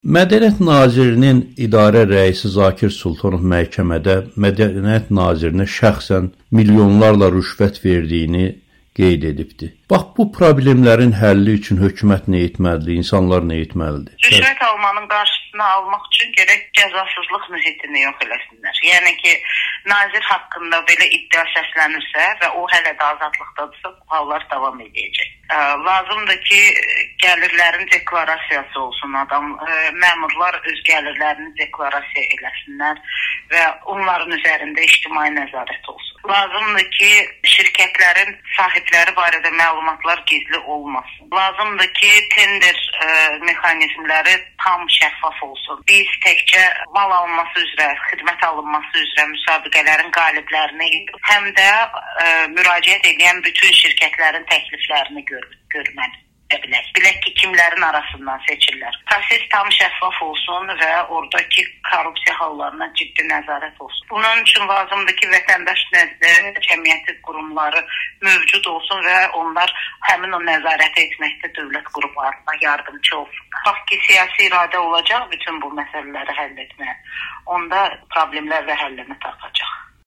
Araşdırmaçı jurnalist Xədicə İsmayıl Amerikanın Səsinə bildirib ki, rüşvət almanın qarşısının alınması üçün ilk növbədə cəzasızlıq mühiti aradan qaldırılmalıdır.